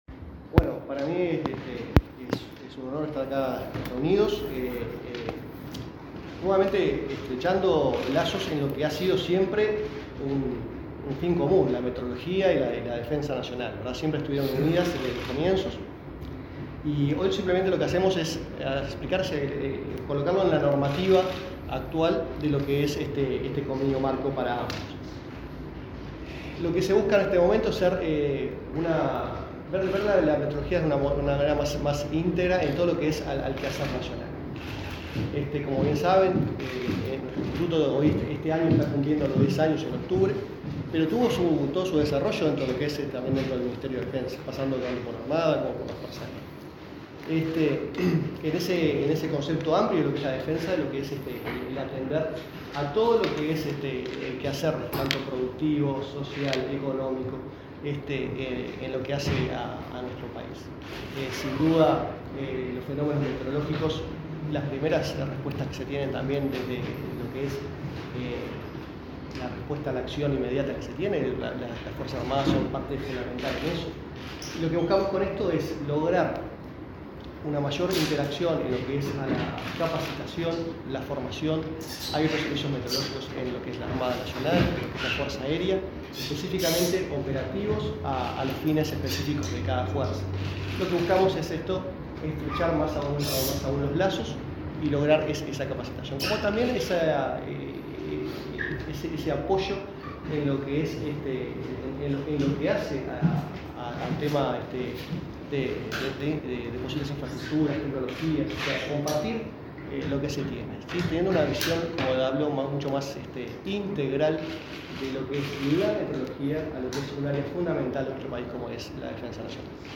Palabras de autoridades en convenio entre Defensa e Inumet